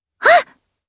Lavril-Vox_Attack1_jp.wav